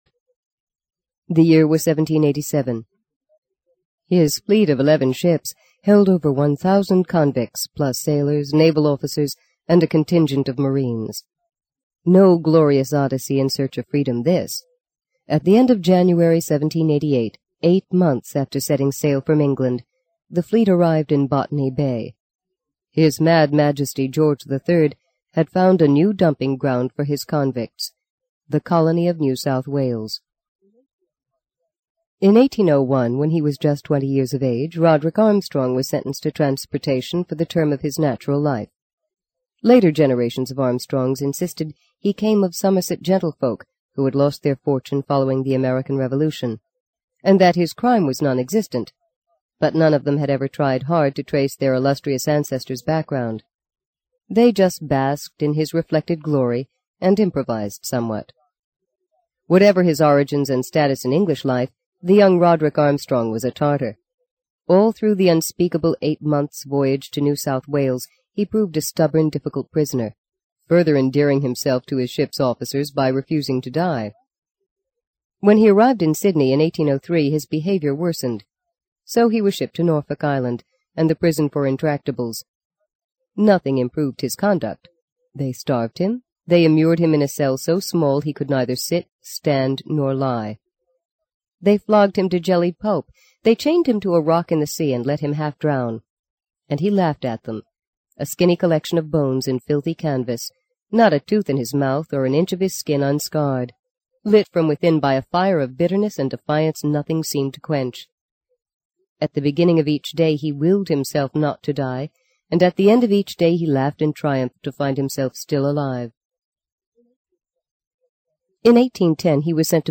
在线英语听力室【荆棘鸟】第二章 02的听力文件下载,荆棘鸟—双语有声读物—听力教程—英语听力—在线英语听力室